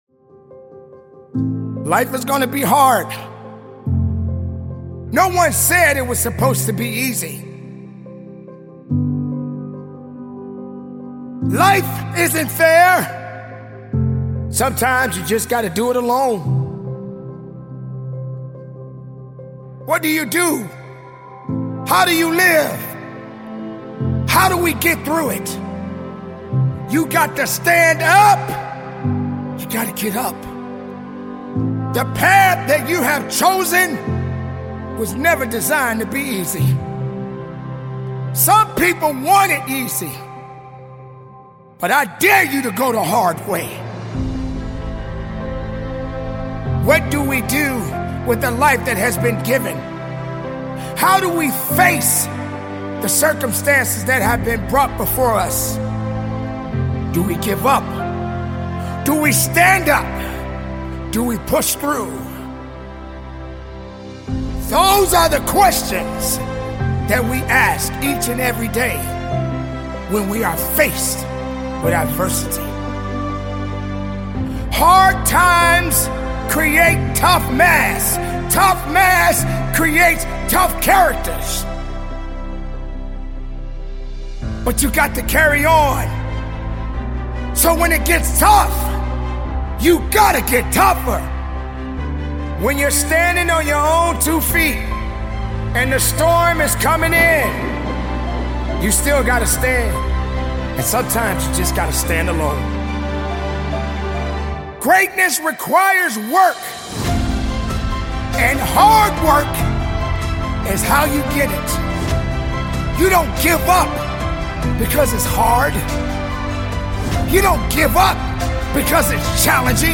Motivational Speeches